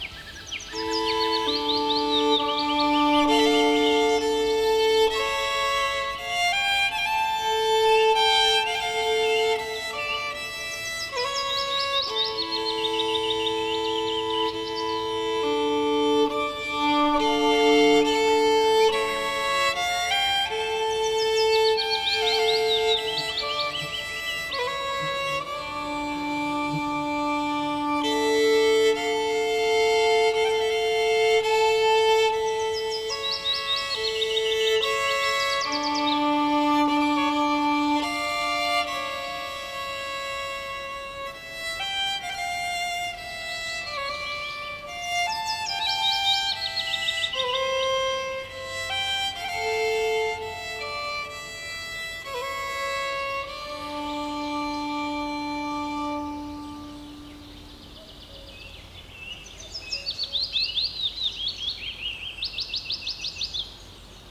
компенсируется скрипкой и клавишами, что, по правде говоря,